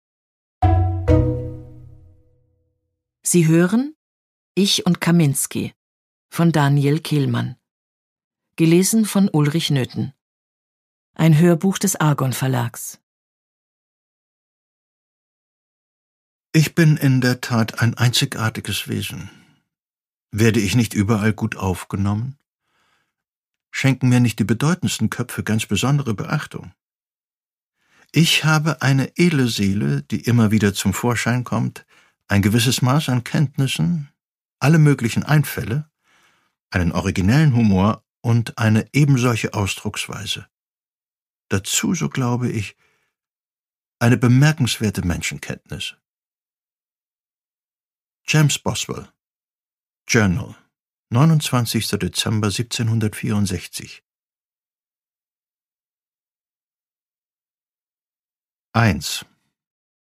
Produkttyp: Hörbuch-Download
Gelesen von: Ulrich Noethen